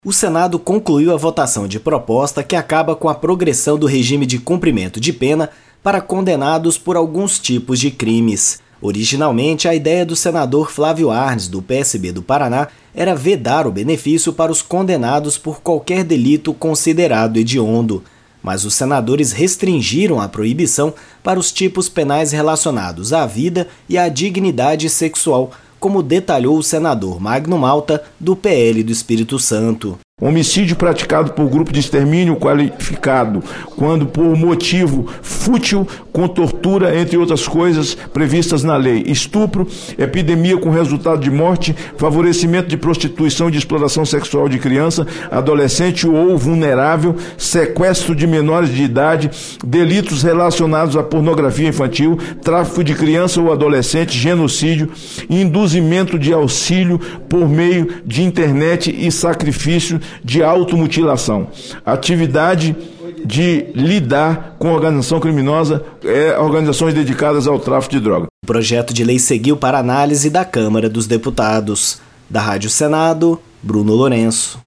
Plenário
Mas o relator, Magno Malta (PL-ES), explicou que os senadores restringiram para os tipos penais relacionados à vida e à dignidade sexual.